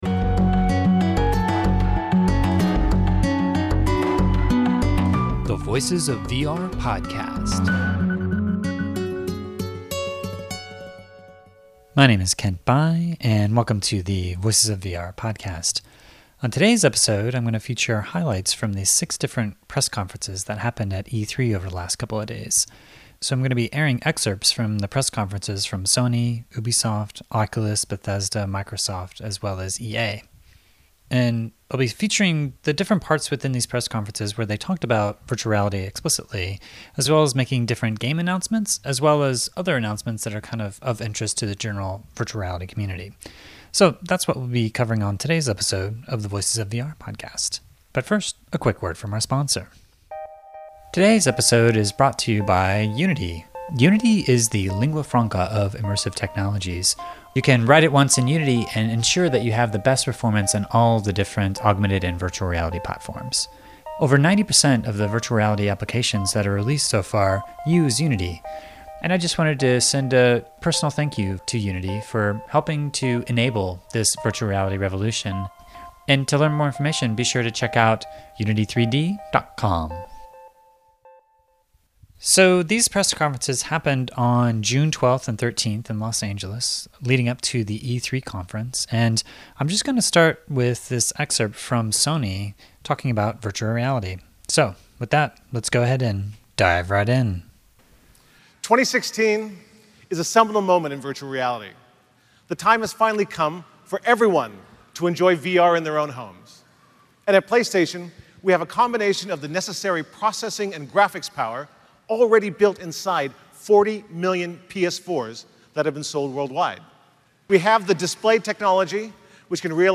#382: Audio Highlights of VR News from 6 Major E3 Press Conferences – Voices of VR Podcast